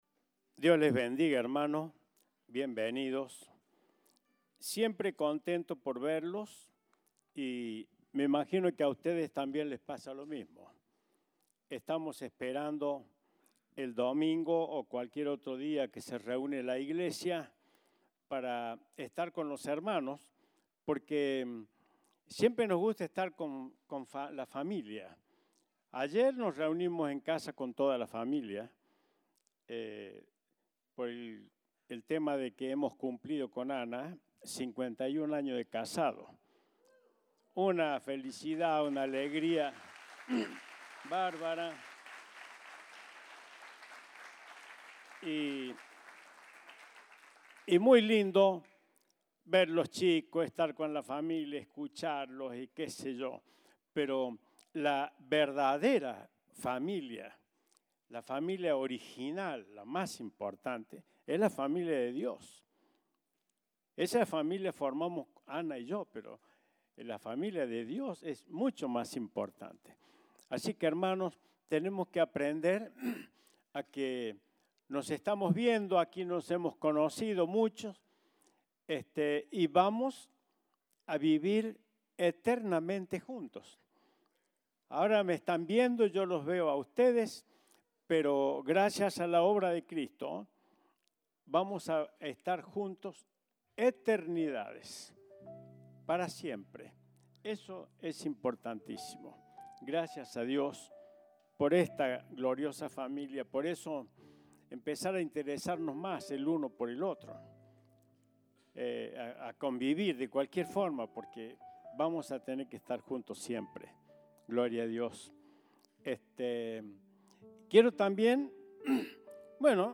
Compartimos el mensaje del Domingo 5 de Junio de 2022.